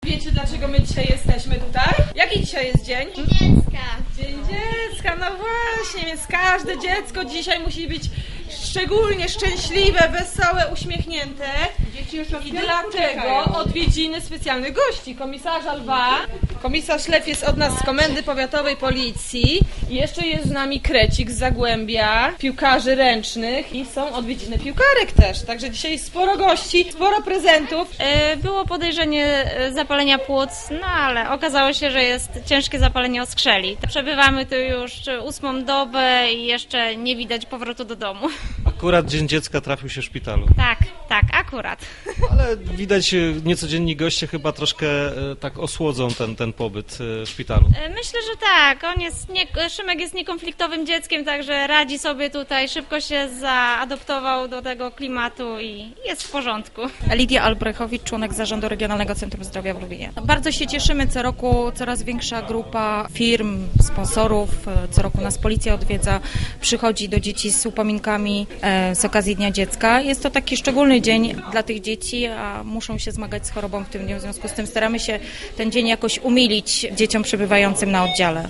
Dzień Dziecka w Regionalnym Centrum Zdrowia w Lubinie: